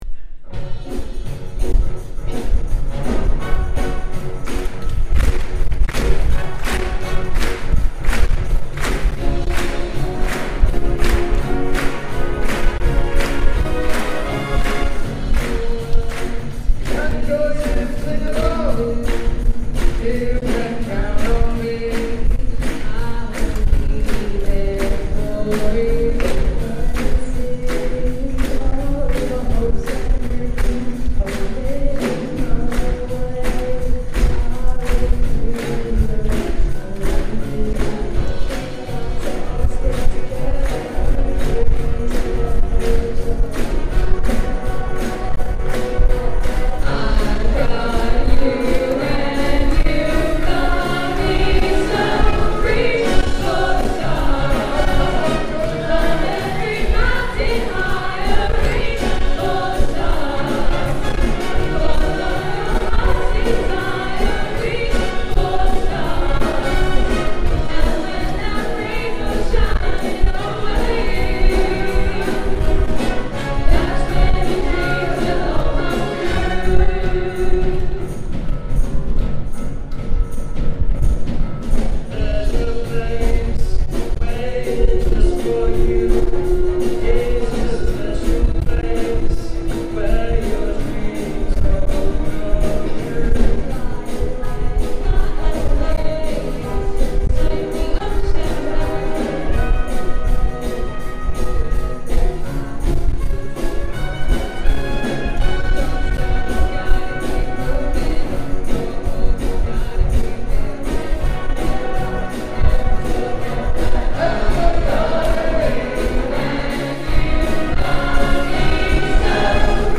Reach - Big Band